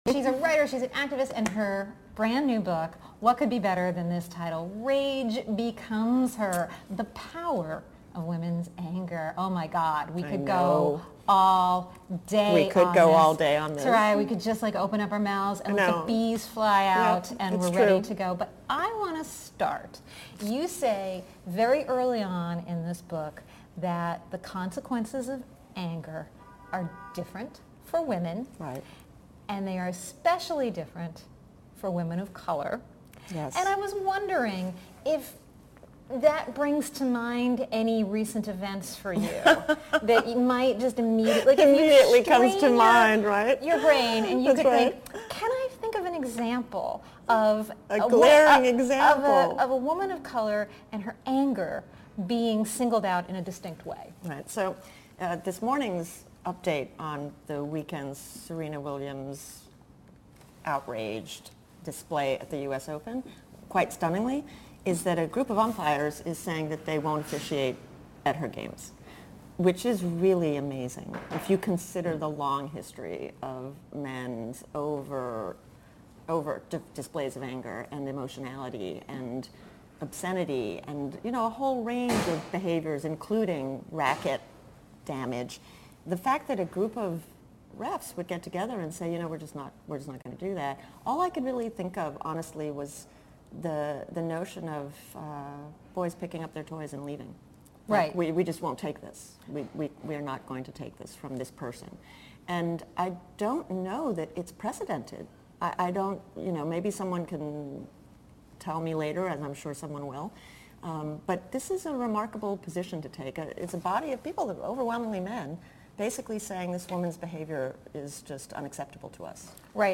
About “Salon Talks” Hosted by Salon journalists, “Salon Talks” episodes offer a fresh take on the long-form interview format, and a much-needed break from the partisan political talking heads that have come to dominate the genre.